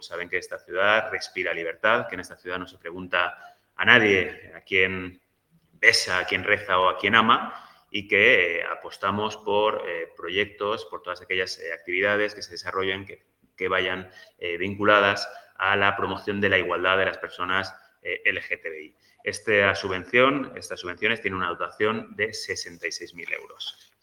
Corte de voz de Juan Carlos Caballero, portavoz del ayuntamiento de Valencia